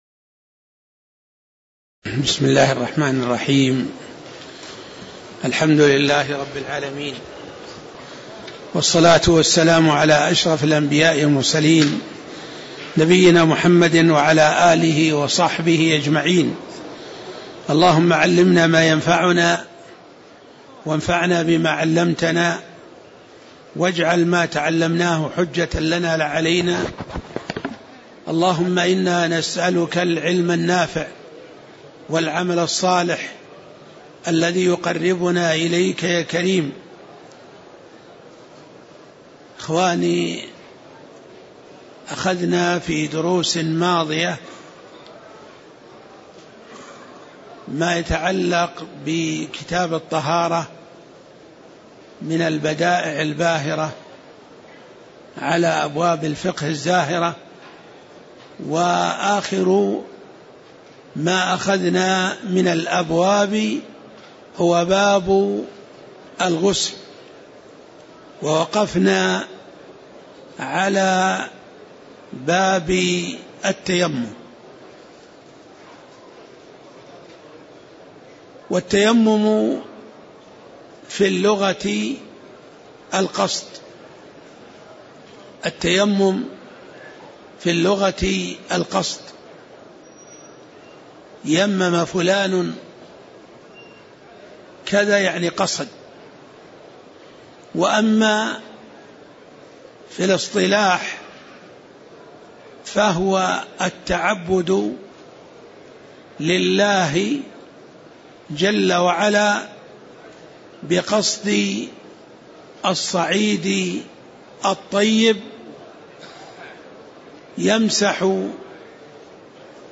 تاريخ النشر ٣ جمادى الآخرة ١٤٣٧ هـ المكان: المسجد النبوي الشيخ